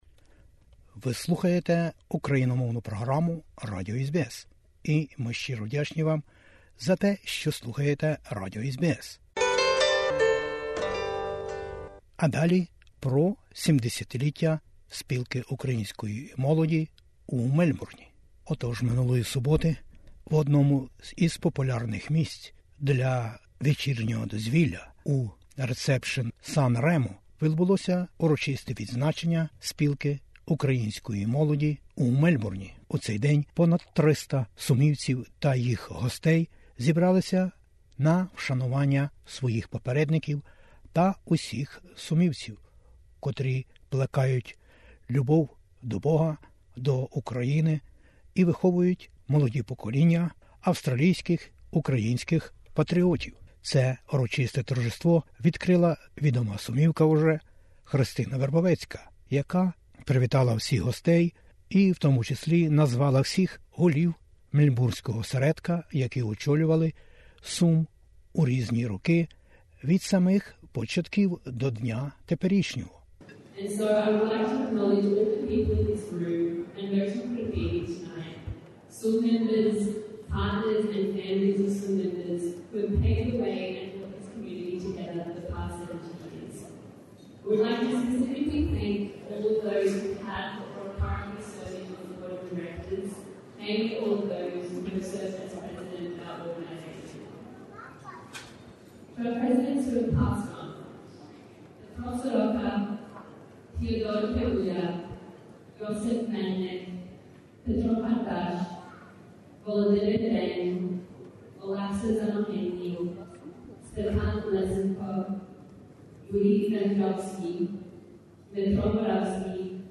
Спілка Української Молоді у Мельбурні відсвяткувала своє 70-ліття - доброчинний баль "Червона калина" відбувся минулої суботи у популярному San Remo Ballroom.